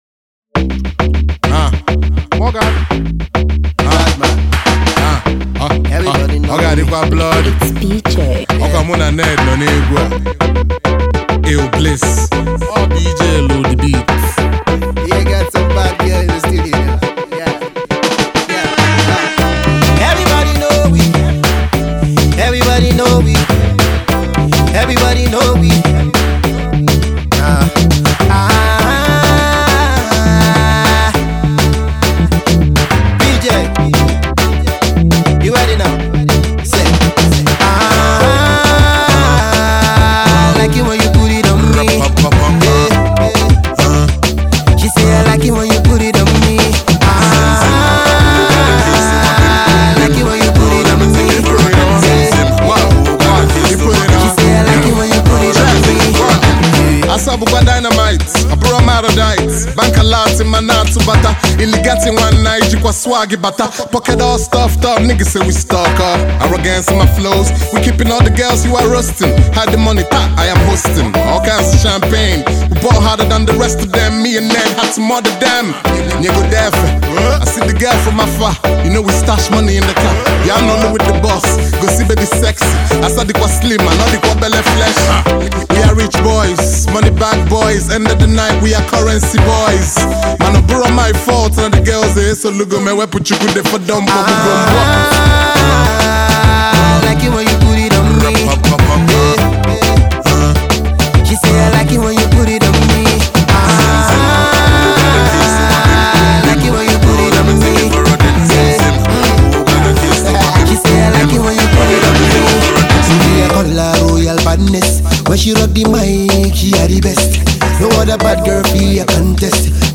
Igbo Rap